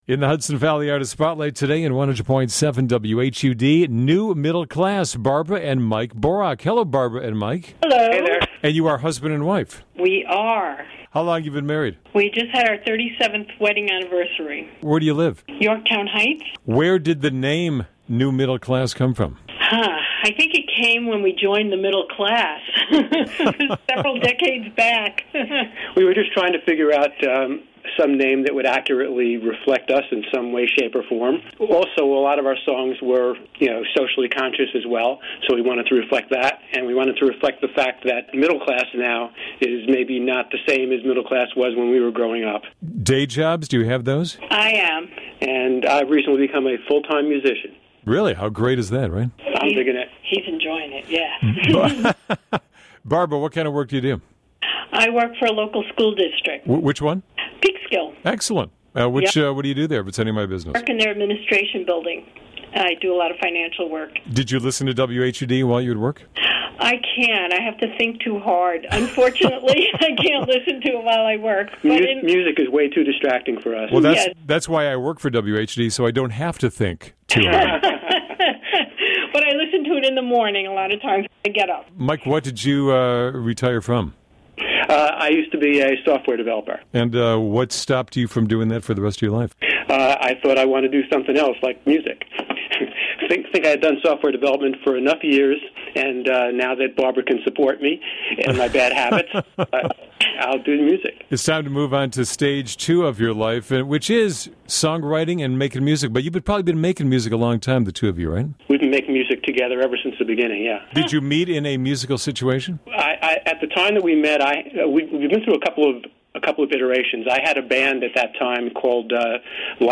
Interview with New Middle Class